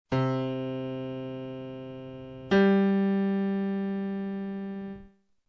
Ascolta questa coppia di suoni: il confronto tra i due suoni ci fa dire che
• il primo è un suono grave;
• il secondo è un suono acuto